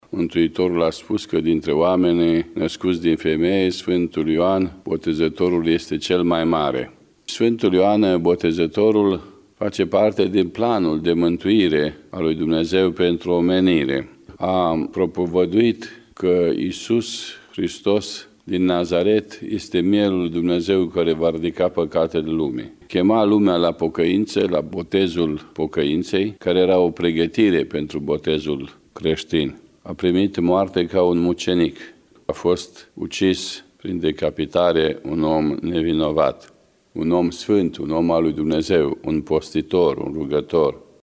Despre Sfântul Ioan Botezătorul ne vorbeşte Patriarhul Bisericii Ortodoxe Române, Prea Fericitul Părinte Daniel: